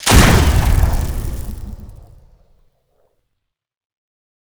pistol1.wav